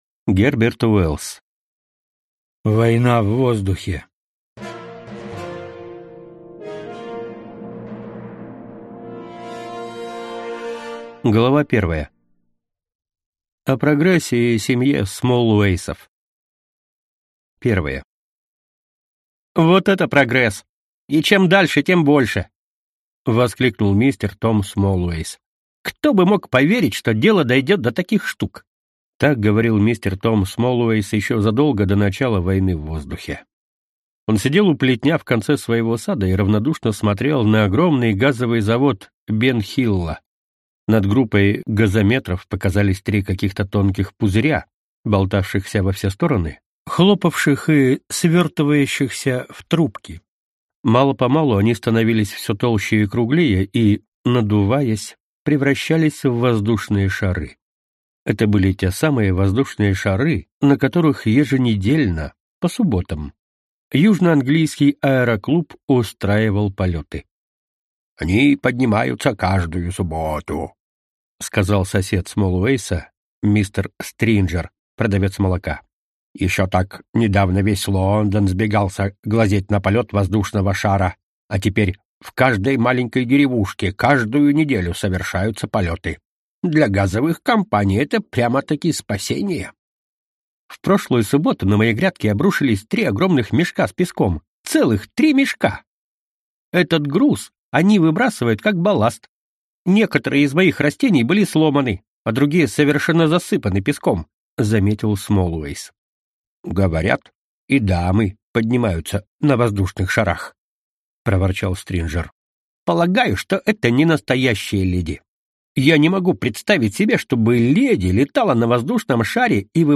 Аудиокнига Война в воздухе | Библиотека аудиокниг